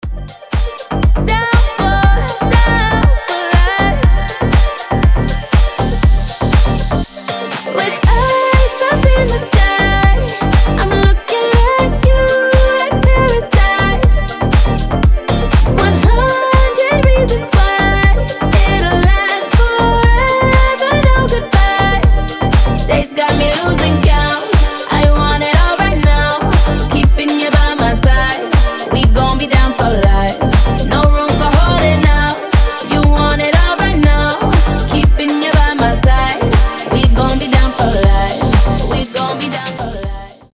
• Качество: 320, Stereo
громкие
женский вокал
deep house
EDM
club
Клубный такой дип-хаус